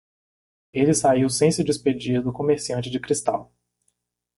/ko.meʁ.siˈɐ̃.t͡ʃi/